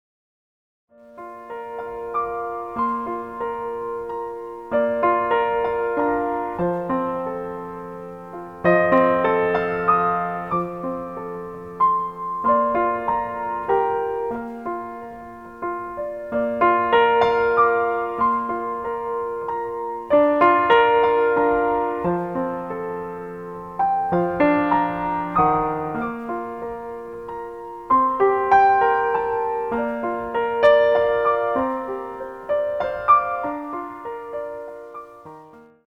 Baby Lullaby